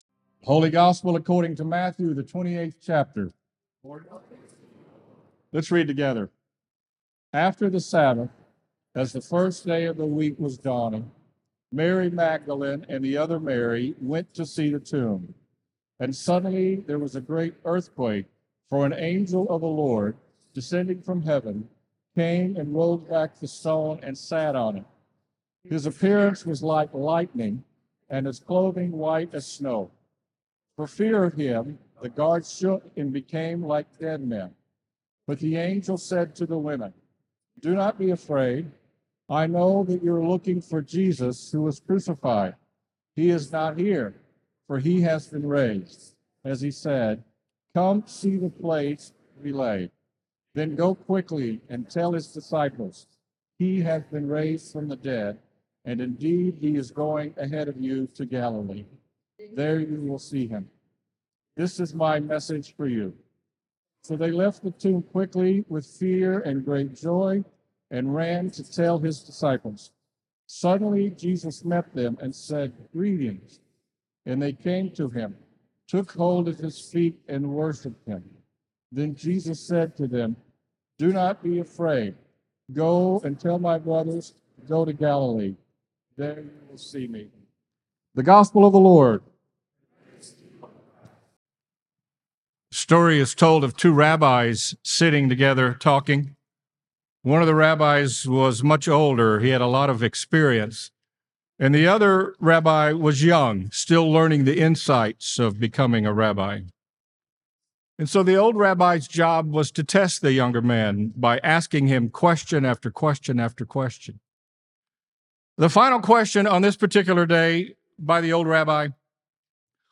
Faith Lutheran Church Knoxville Sermon Podcast